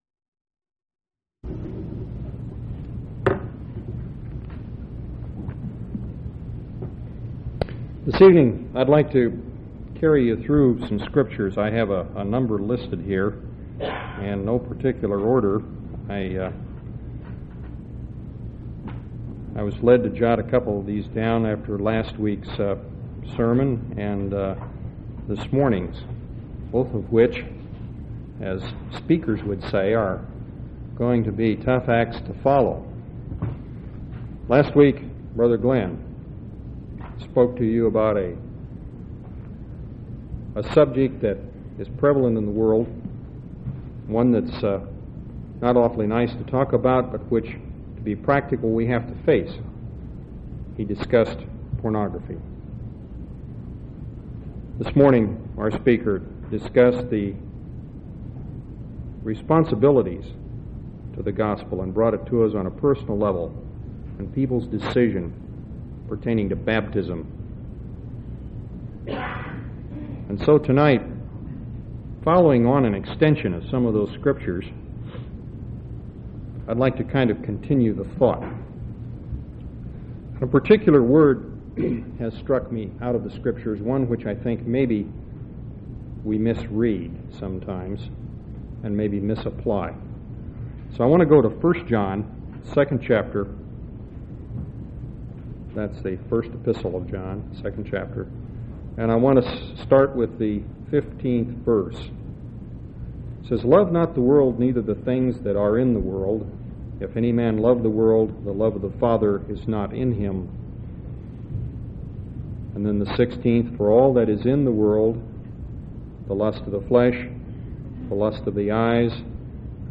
9/28/1986 Location: Temple Lot Local Event